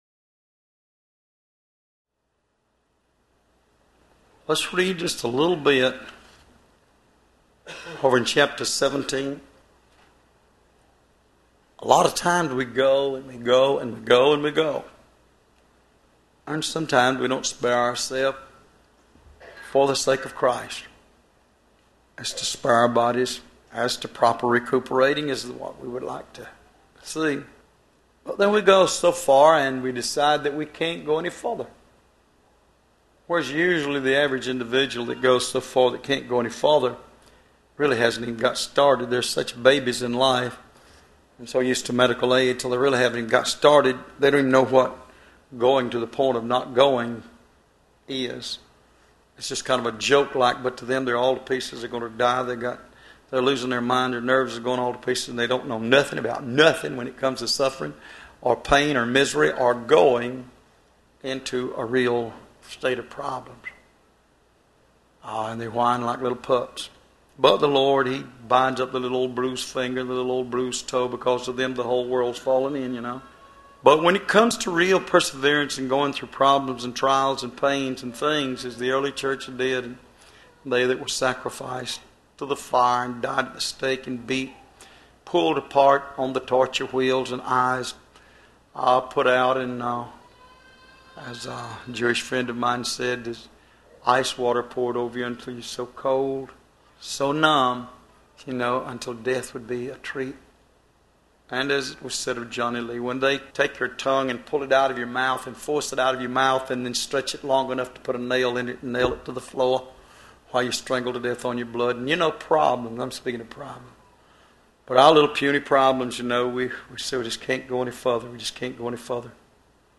Location: Love’s Temple in Monroe, GA USA